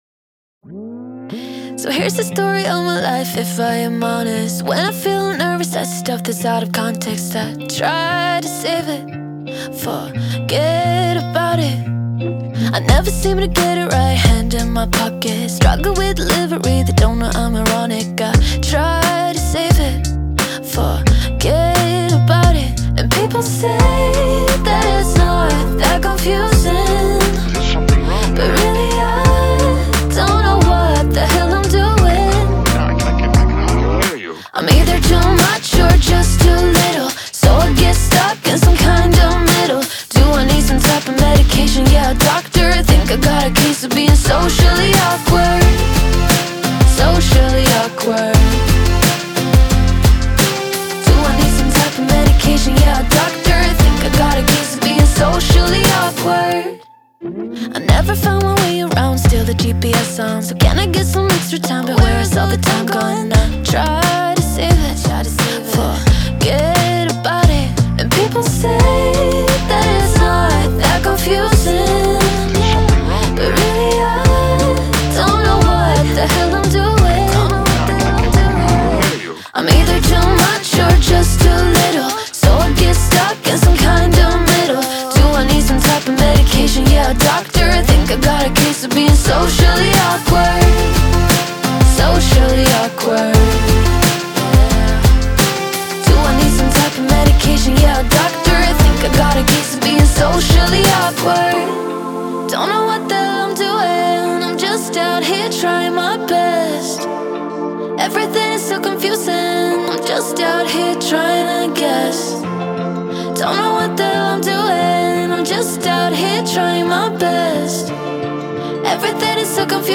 это яркая и запоминающаяся песня в жанре инди-поп